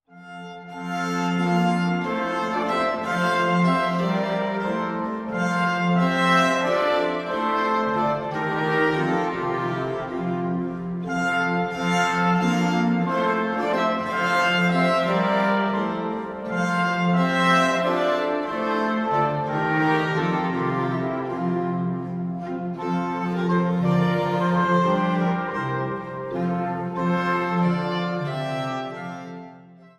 Choral für sechs Stimmen